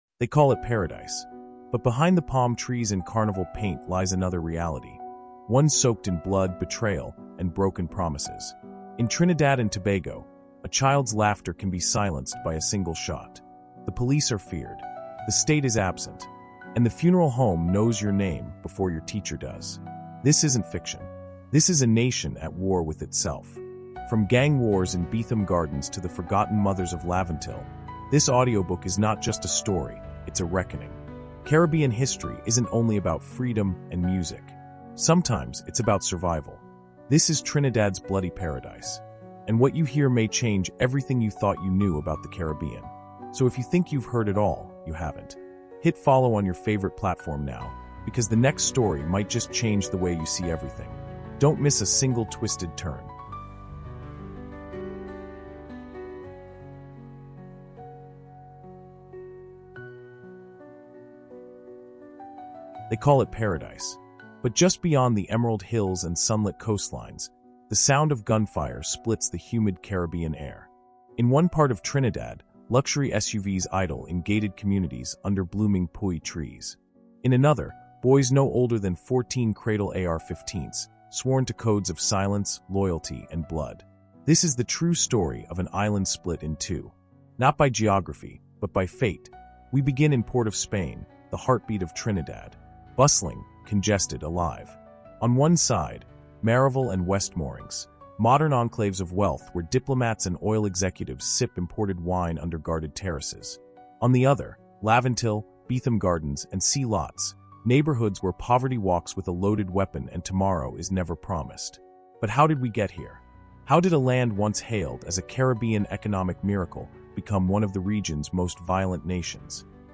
CARIBBEAN HISTORY: Trinidad’s Bloody Paradise — A Nation at War With Itself is a deeply immersive, emotionally charged 8-part documentary audiobook exploring the hidden truths behind Trinidad and Tobago’s escalating crime crisis. This powerful caribbean podcast blends raw firsthand stories with expert historical analysis, revealing the roots of violence, political decay, and cultural resilience that define the region today. Narrated with cinematic depth and historical accuracy, the series traces the complex intersections of caribbean history, caribbean identity, and colonization that shaped the island’s modern struggles.